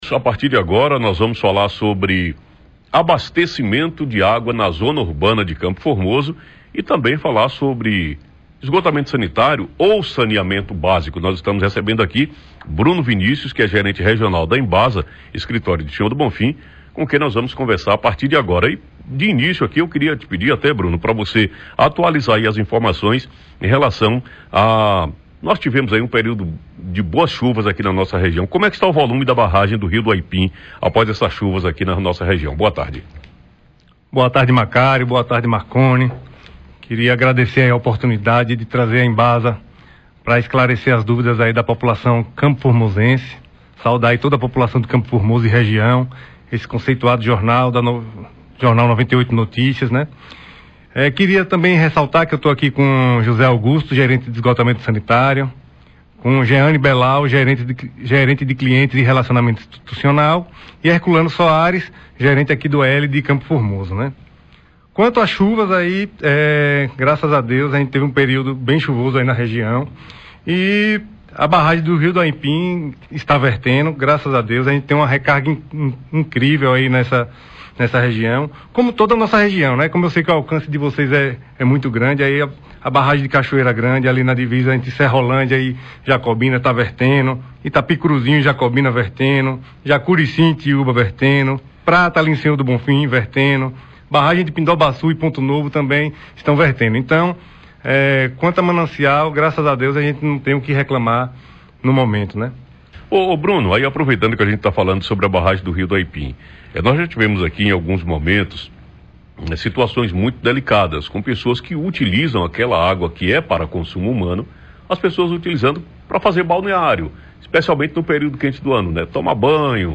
entrevista-com-o-pessoal-da-embasa.mp3